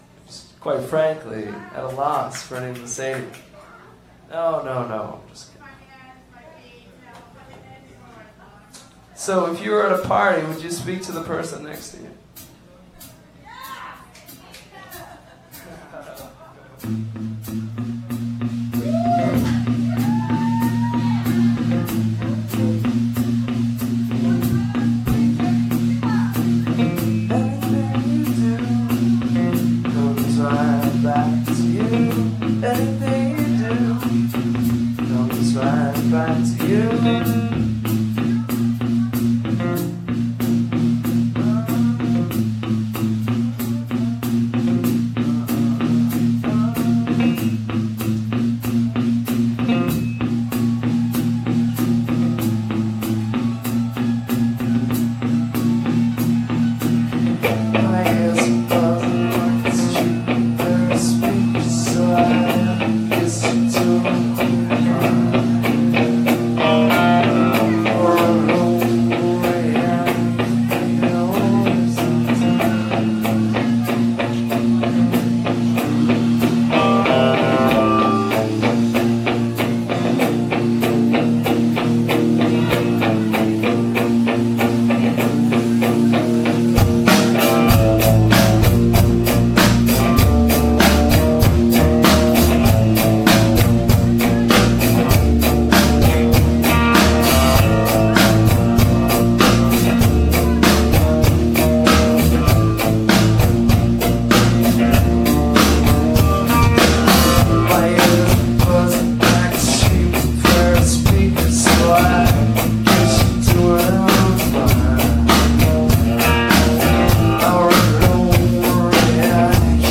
the casbah san diego september 17 2000